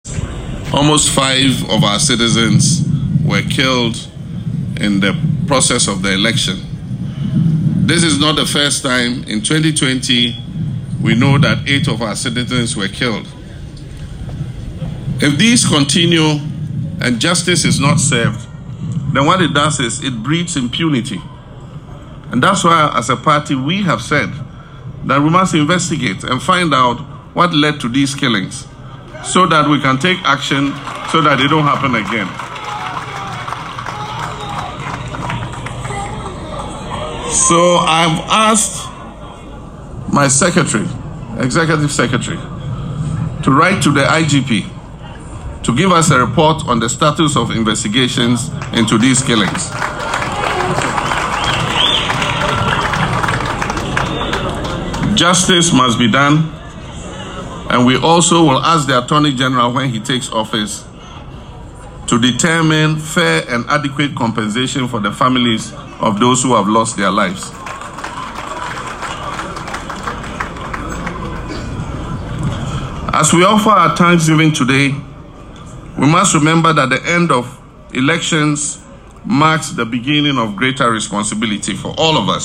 Speaking at the National Muslim Prayer and Thanksgiving event at the National Mosque in Kanda, Accra, on Friday, January 10, President Mahama reaffirmed his administration’s commitment to justice and accountability for the tragic incidents.